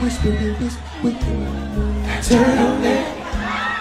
Whisper Turtleneck Sound Button - Free Download & Play